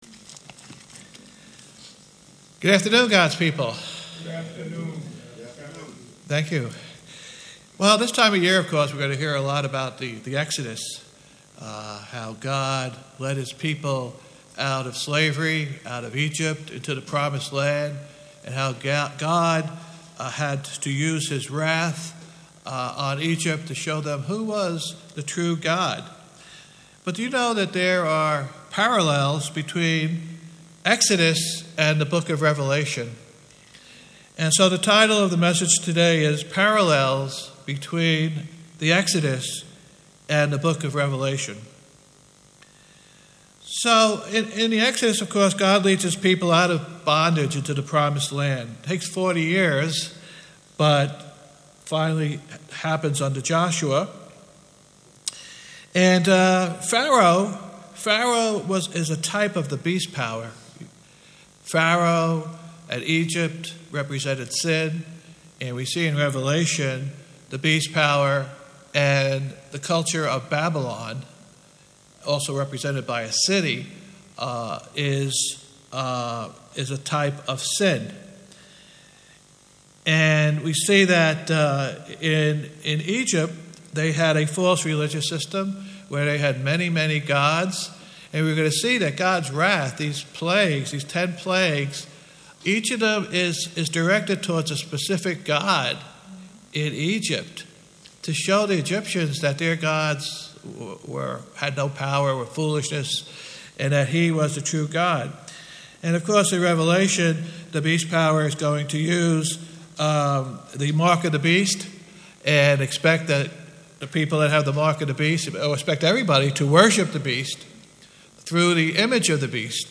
Sermons
Given in New Jersey - North New York City, NY